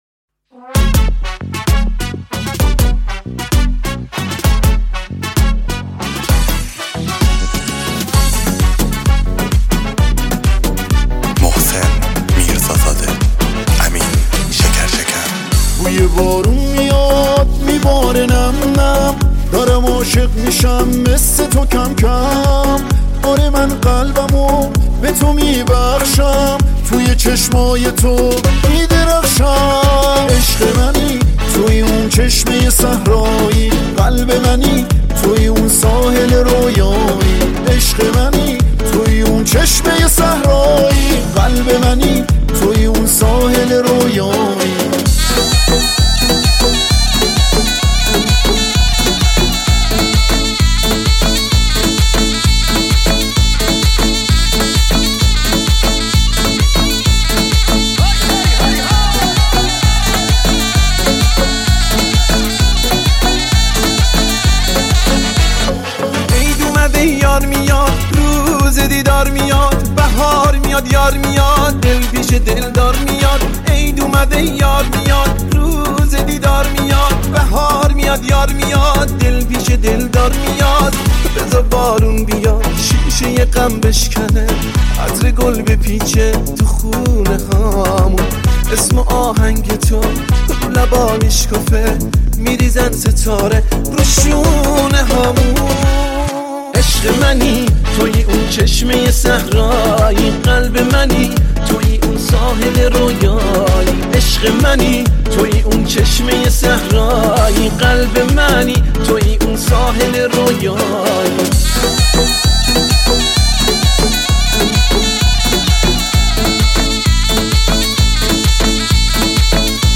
آهنگ کرمانجی